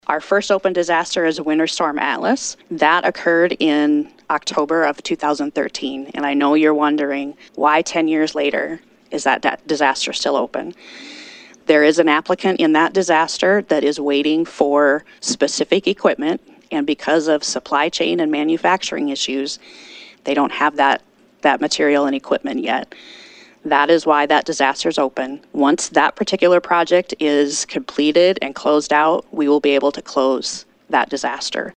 Kristi Turman, Director of the State Office of Emergency Management, says they are still working off a storm from 2013.